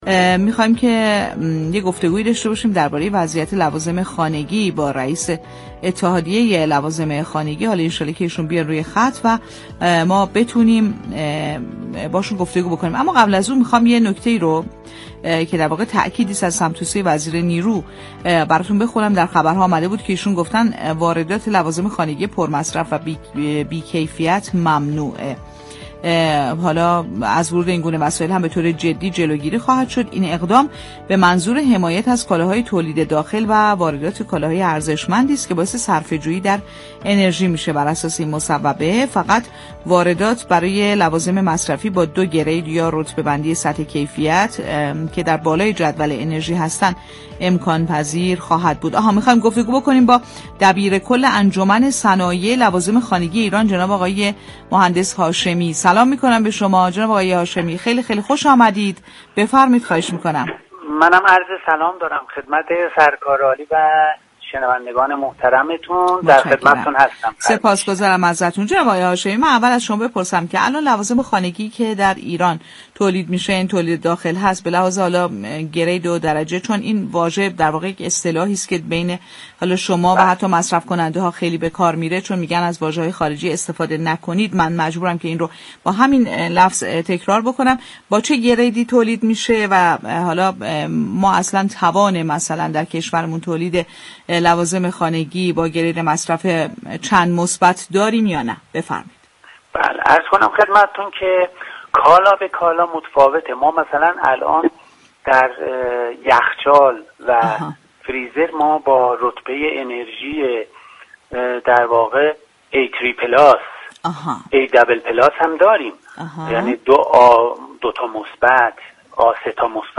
در گفت و گو با «بازار تهران»